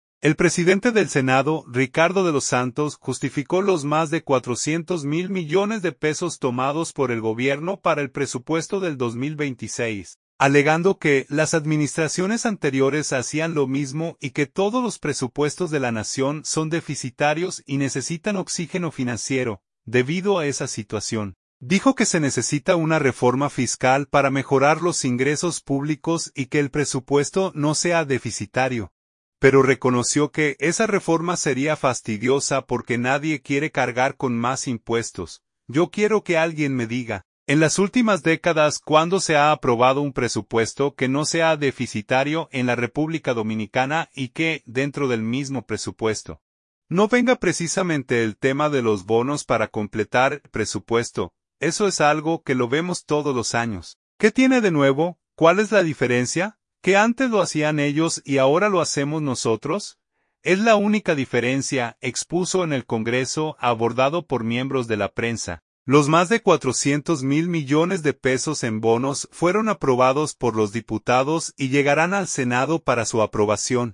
Es la única diferencia”, expuso en el Congreso, abordado por miembros de la prensa.